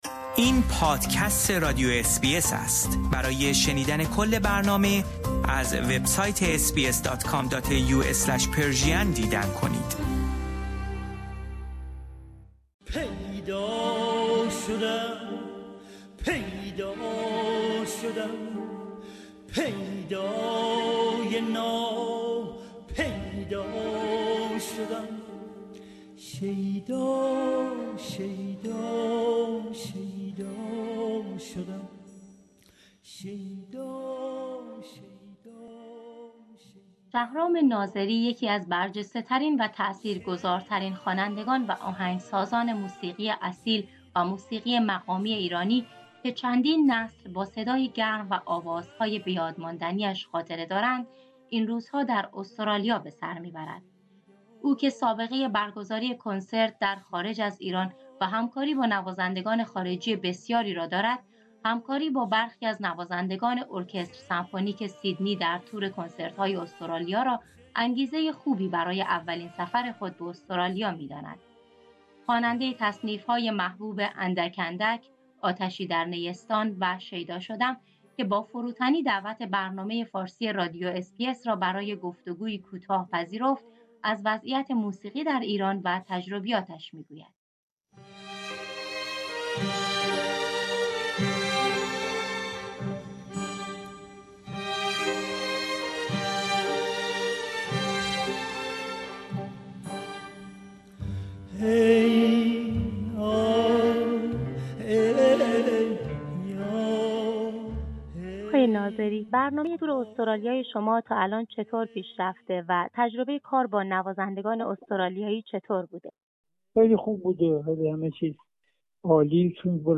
او که سابقه‌برگزاری کنسرت در خارج از ایران و همکاری با نوازندگان خارجی بسیاری را دارد، همکاری با برخی از نوازندگان ارکستر سمفونیک سیدنی در تور کنسرت‌‌های استرالیا را انگیزه‌خوبی برای نخستین سفر خود به استرالیا می‌داند. خواننده‌تصنیف‌های محبوب "اندک اندک"، "آتشی در نیستان" و "شیدا شدم" در گفتگو با برنامه فارسی رادیو اس بی اس از وضعیت موسیقی در ایران و تجربیاتش می‌گوید.